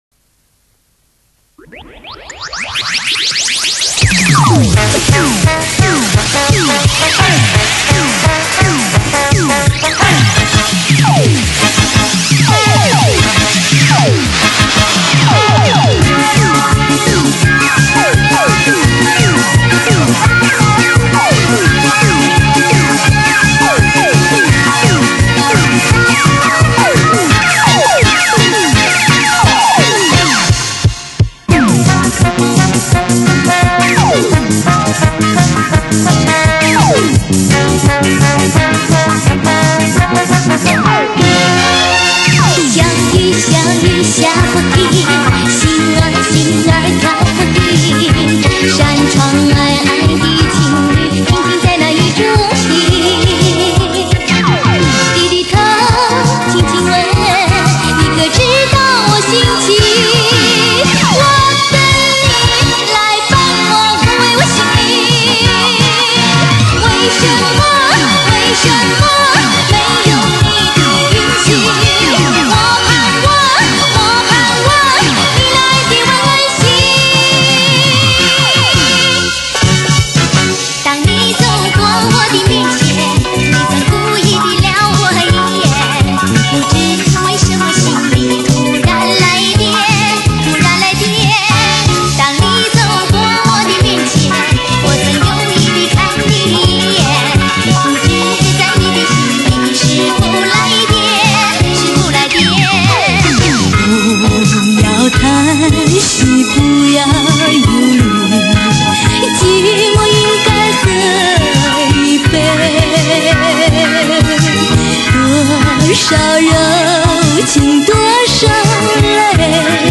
32路24轨引进最新设备录制
试听：节选片段 [64k/wma]